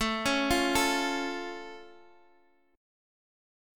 F+/A chord {x x 7 6 6 5} chord
F-Augmented-A-x,x,7,6,6,5-8.m4a